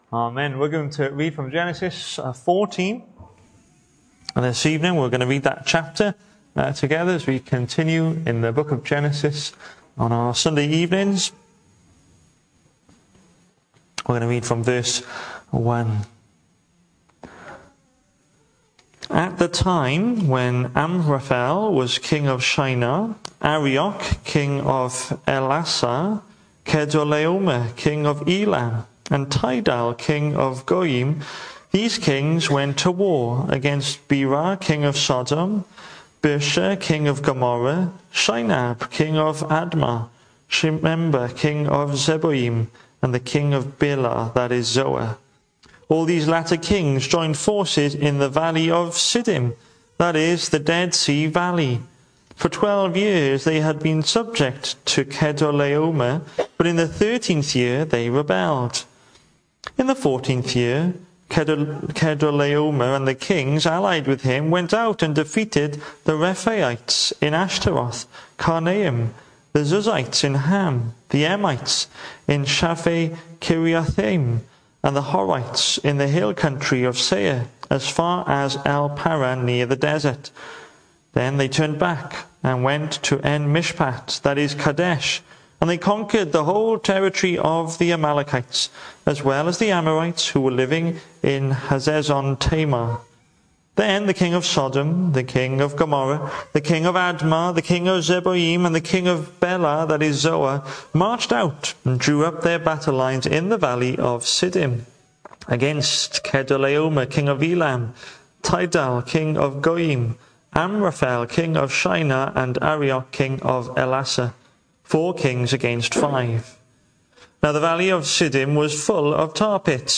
The 21st of September saw us hold our evening service from the building, with a livestream available via Facebook.